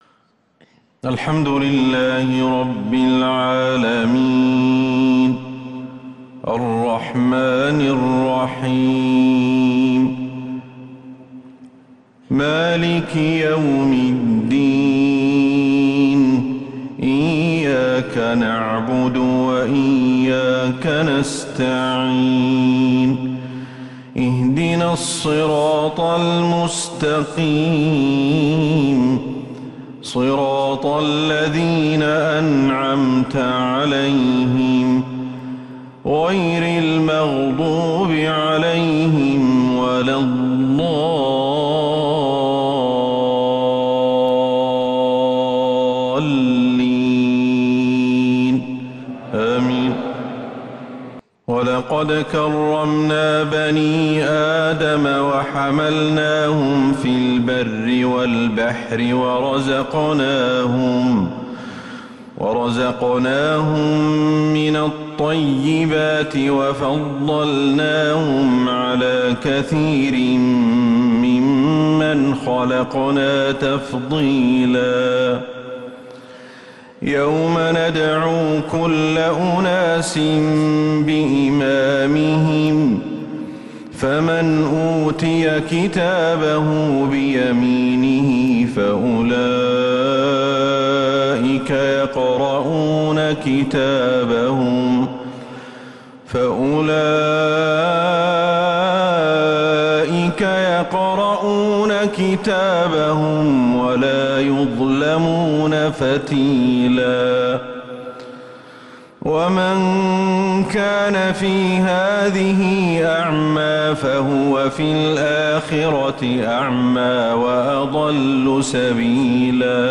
عشاء الأربعاء 26 محرم 1444هـ ما تيسر من سورة {الإسراء} > 1444هـ > الفروض - تلاوات الشيخ أحمد الحذيفي